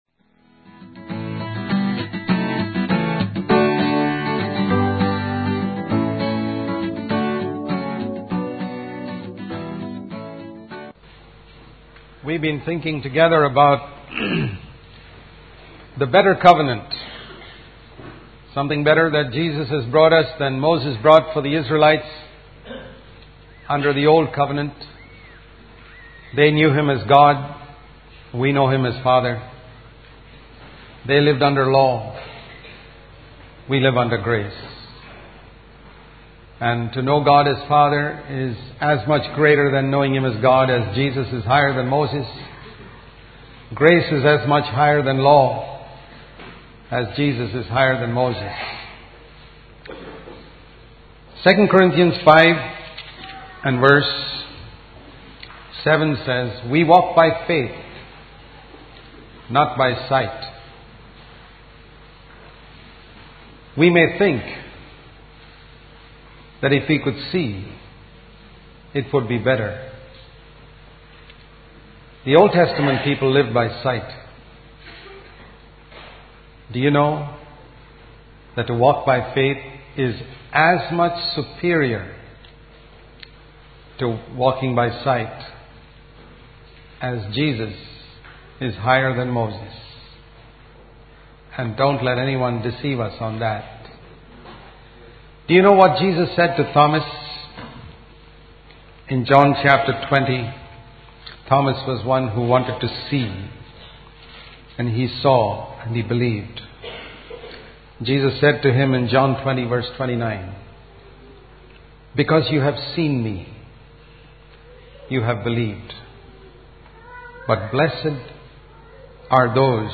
In this sermon, the speaker emphasizes the importance of faith and the examples of faith in the Old Testament.